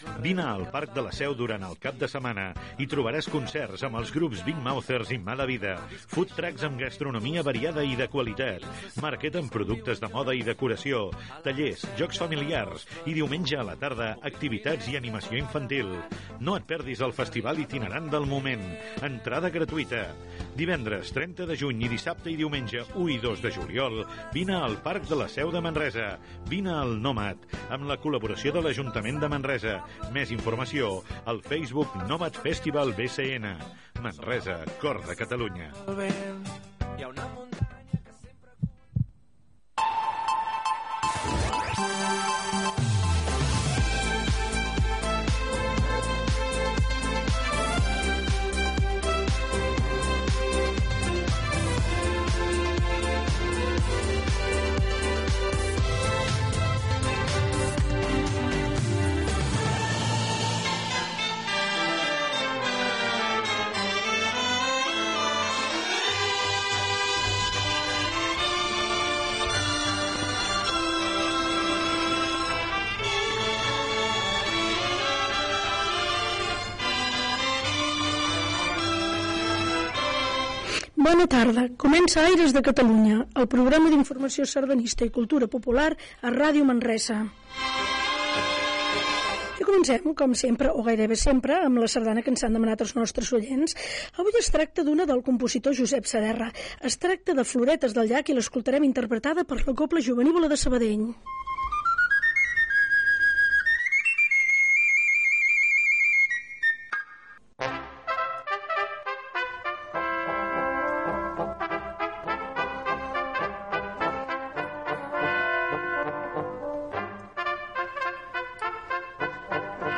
Publicitat, indicatiu musical de la Cadena Ser, sardana de sintonia, presentació, la sardana demanada i sardana de lluiment
Musical